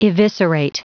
Prononciation du mot eviscerate en anglais (fichier audio)
Prononciation du mot : eviscerate